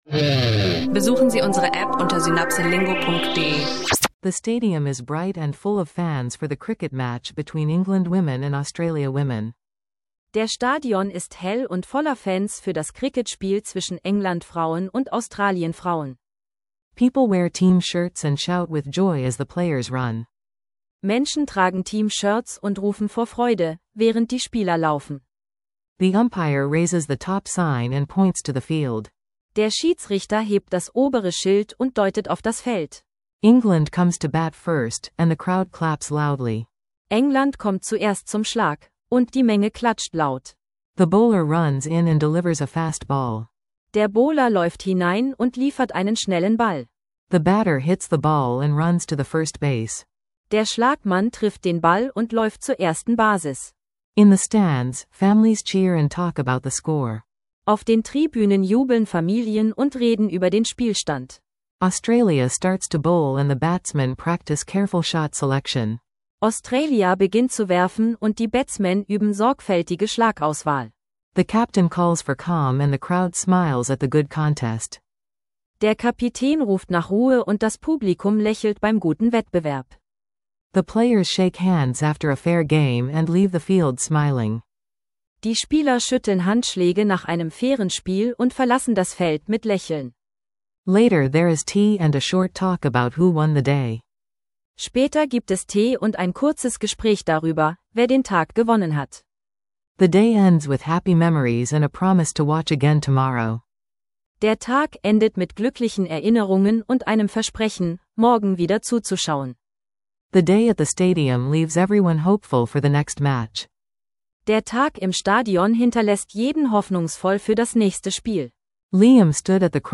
Von Cricket-Vokabular bis Karrieredrang: Lerne Englisch mit Alltagstexten und praxisnahen Dialogen.